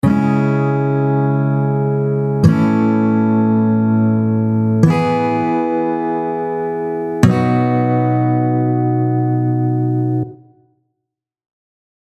4 Chord progression – C#m, A, E, B.
33-progression-2.mp3